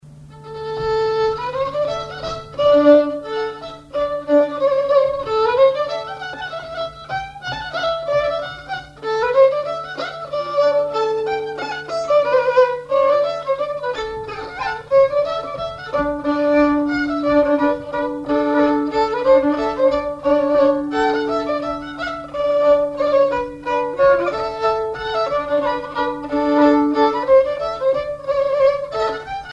violoneux, violon
danse : java
instrumentaux au violon mélange de traditionnel et de variété
Pièce musicale inédite